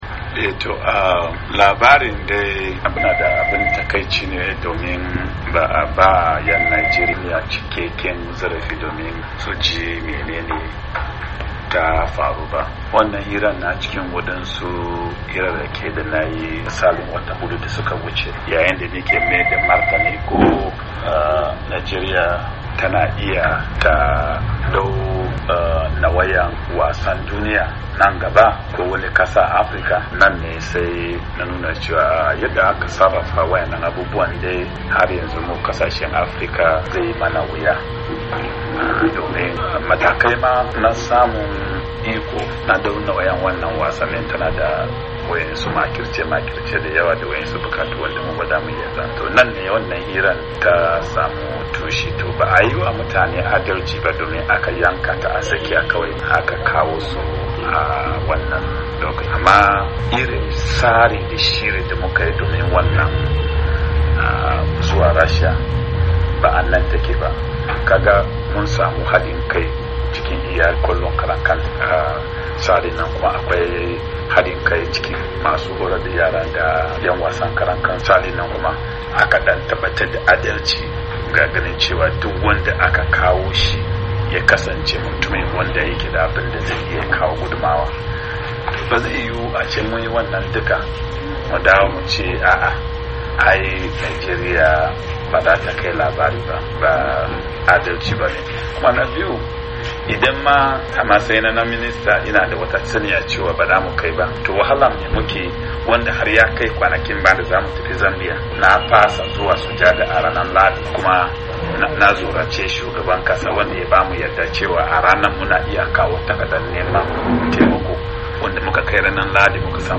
A wata hira da wakilin Muryar Amurka